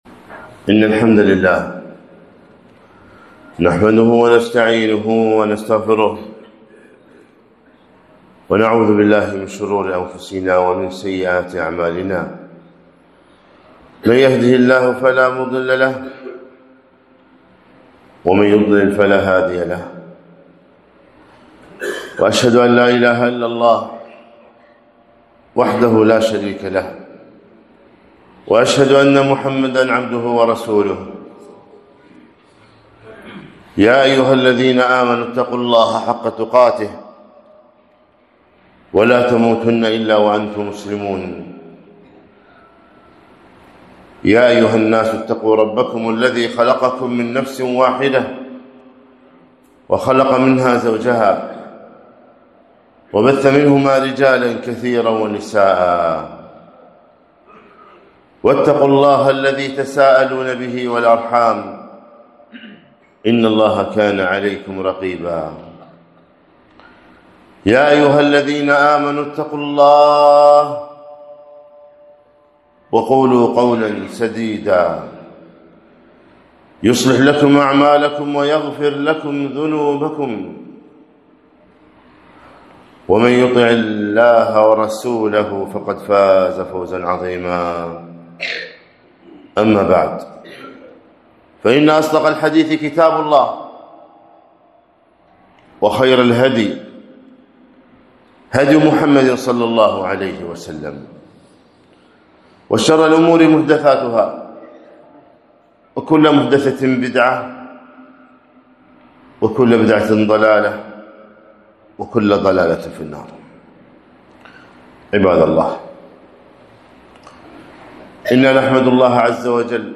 خطبة - كيف أنت مع القرآن؟